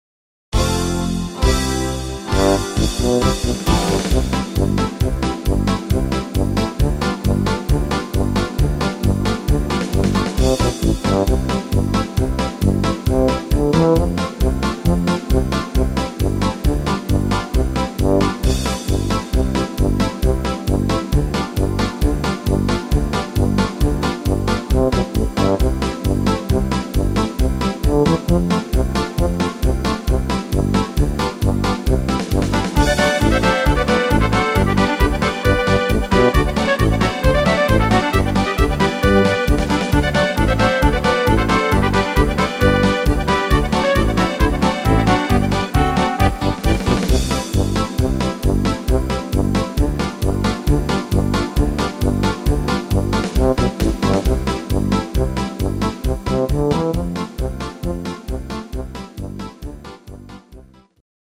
Rhythmus  Polka
Art  Instrumental Akkordeon, Volkstümlich